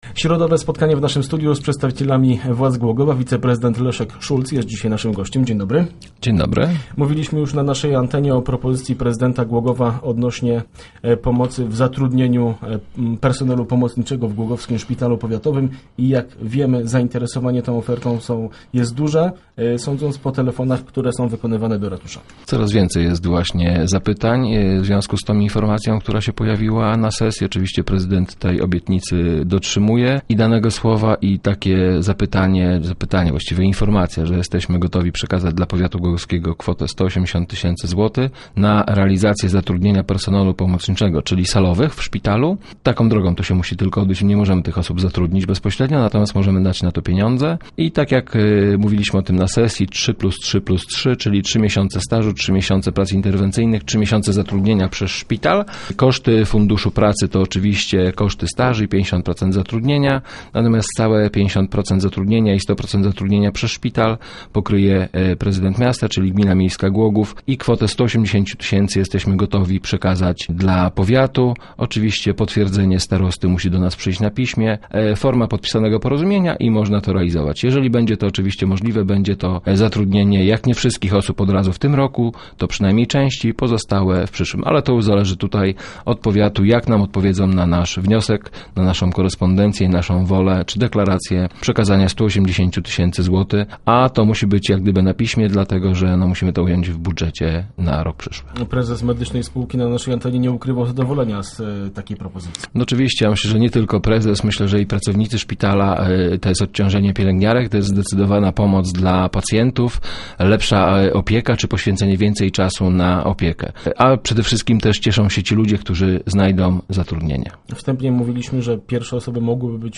O potężnej inwestycji WPEC na osiedlu Śródmieście oraz o zatrudnieniu salowych w Głogowskim Szpitalu Powiatowym rozmawialiśmy w studiu Radia Elka z wiceprezydentem miasta Leszkiem Szulcem.